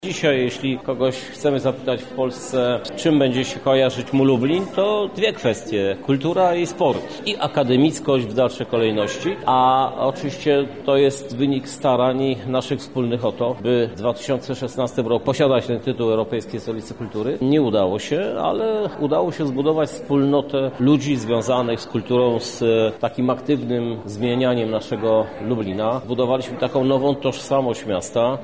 Krzysztof Żuk– mówi Krzysztof Żuk, Prezydent Miasta Lublin.